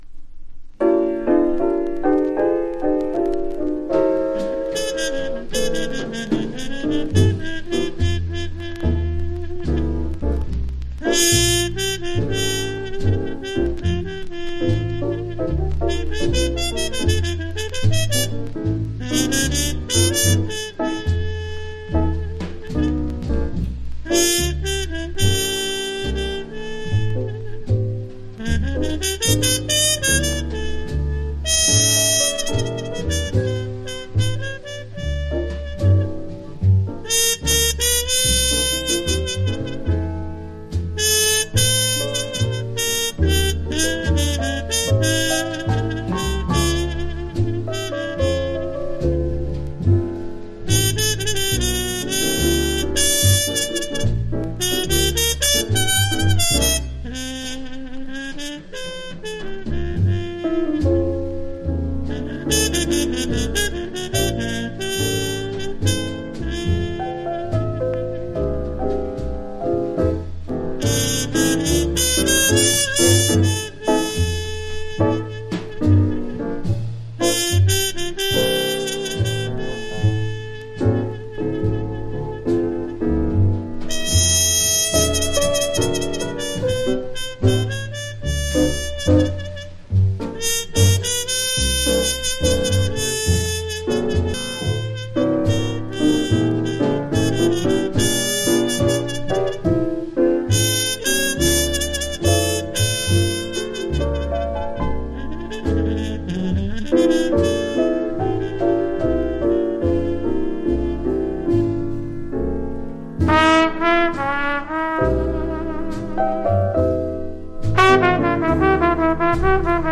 （プレス・小傷により少しチリ、プチ音ある曲あり）
Genre US JAZZ